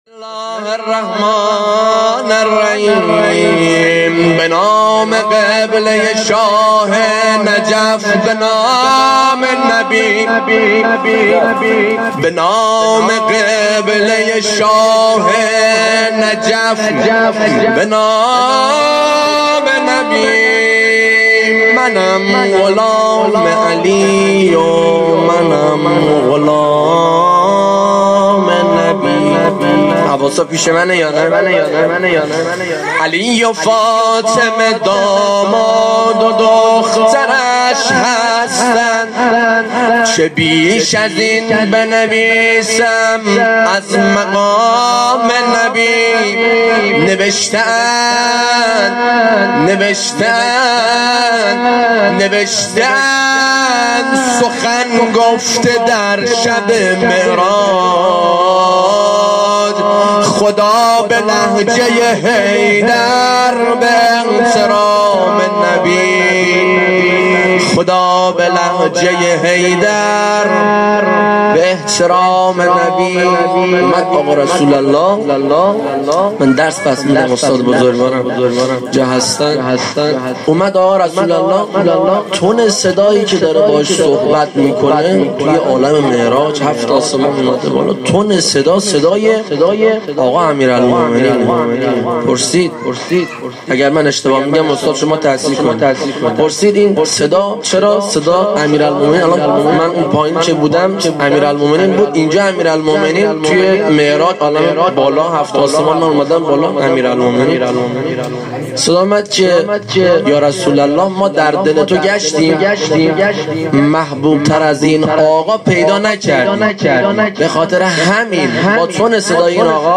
مدح
جشن ها